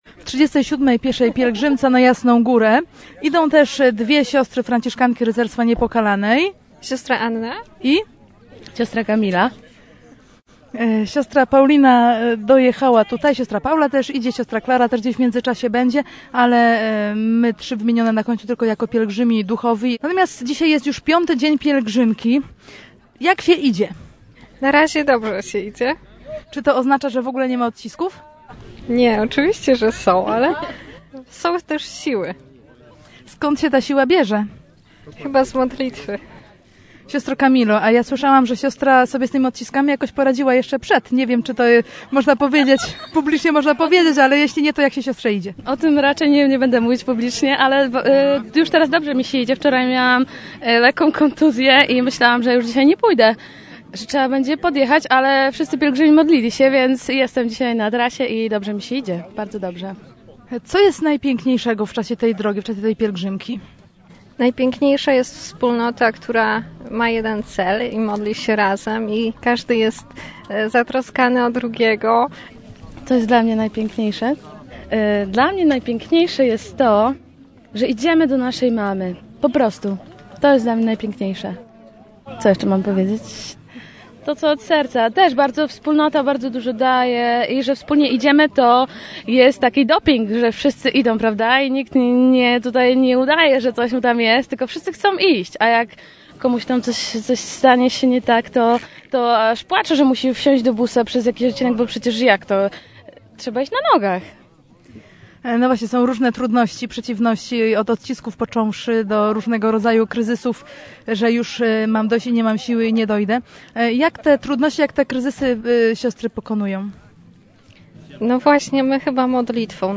rozmowa-siostry.mp3